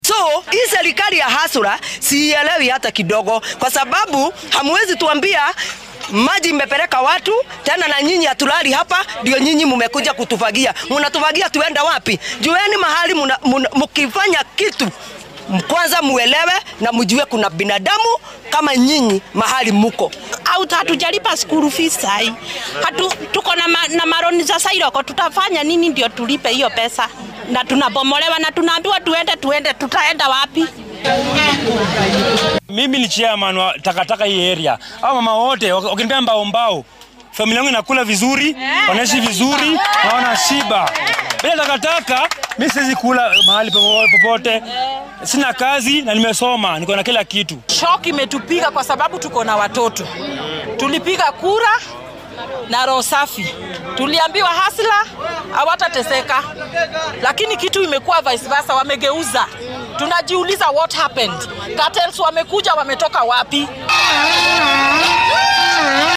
Qaar ka mid ah dadka ay arrintan saameysay ayaa warbaahinta la hadlay.